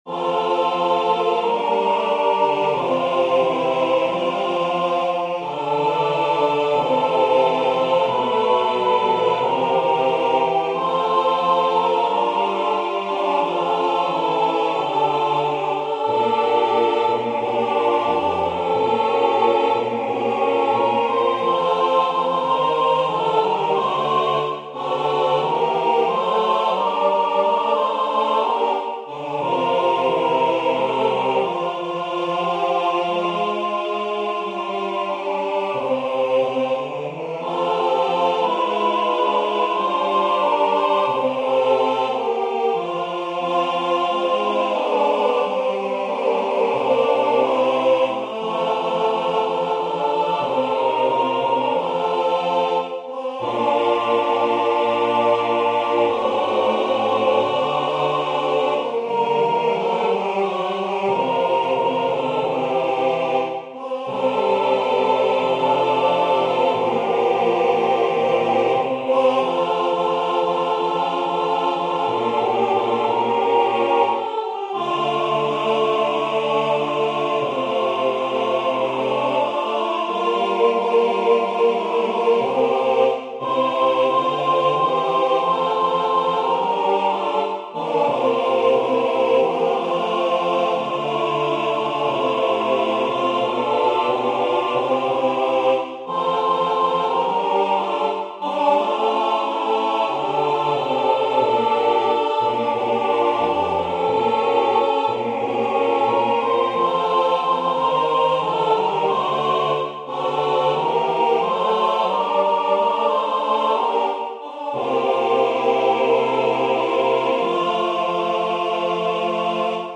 4-stimmiger Satz (SATB)
S A T B